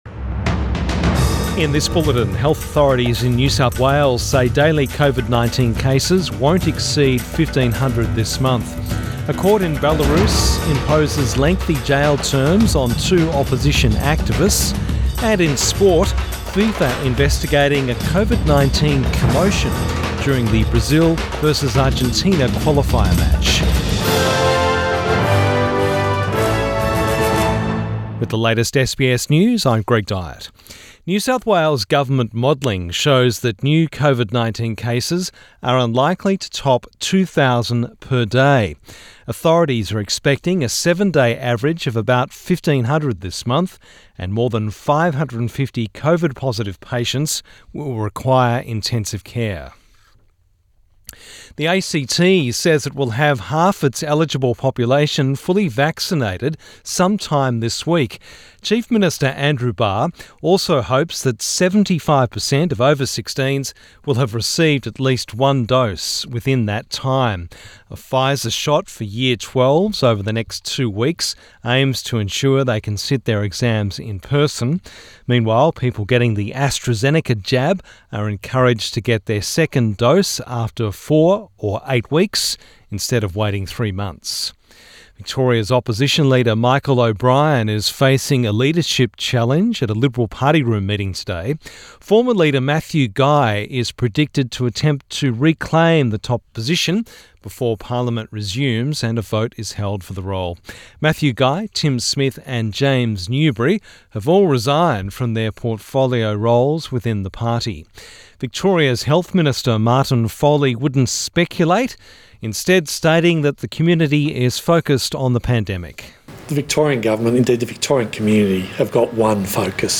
AM bulletin 7 September 2021